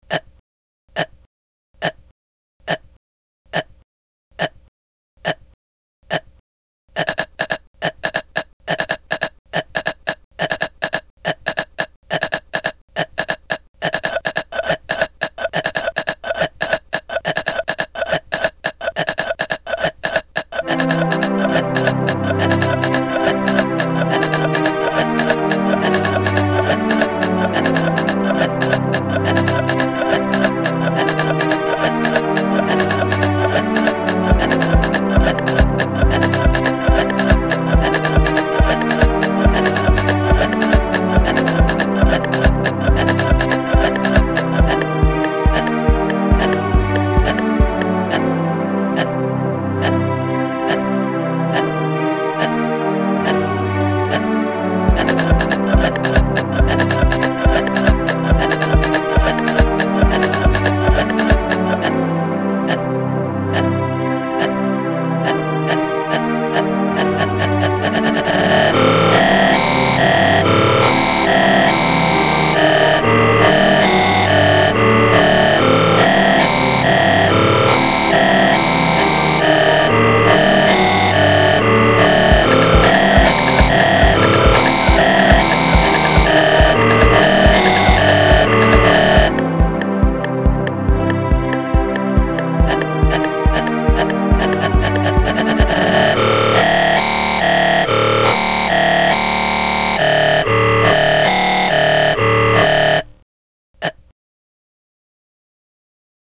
Song based on coughing noise